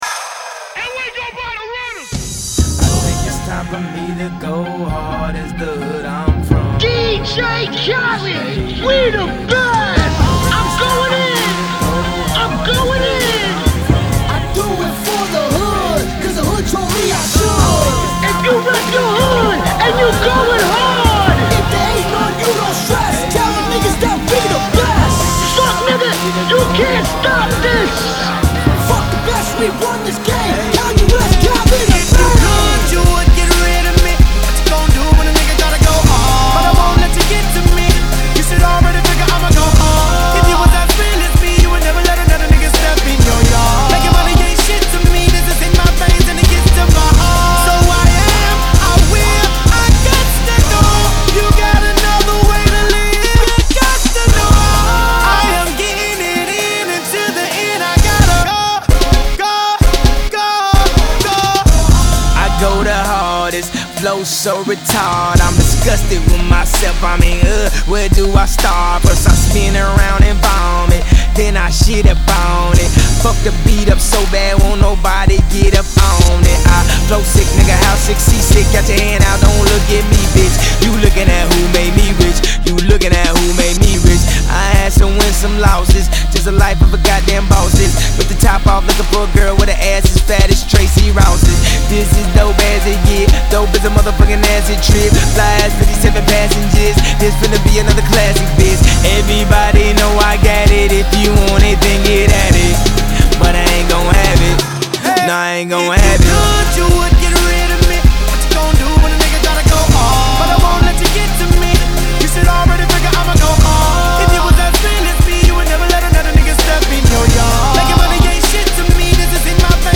Hot track with the synthesizer and all.